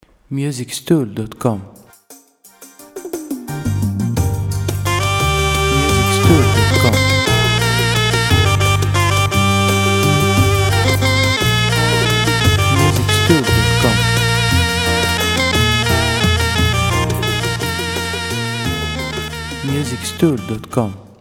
• Type : Instrumental
• Bpm : Allegretto
• Genre : Oriental / Folk/ Country